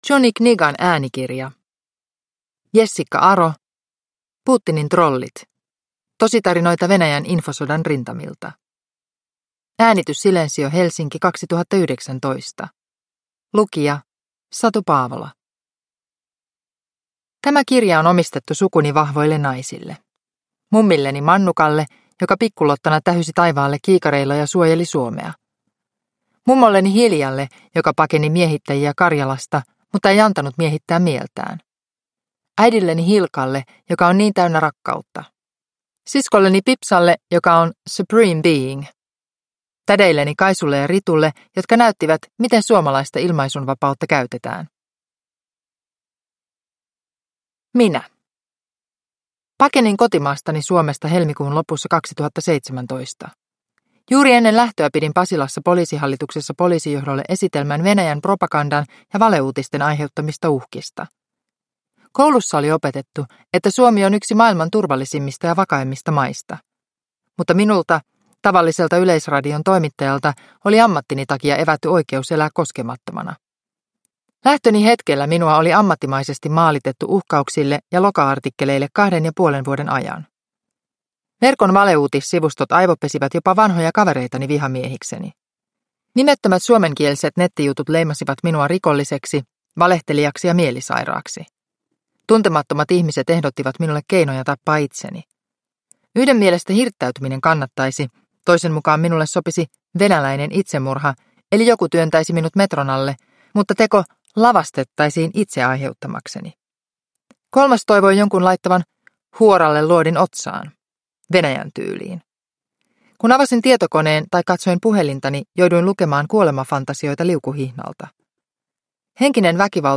Putinin trollit – Ljudbok – Laddas ner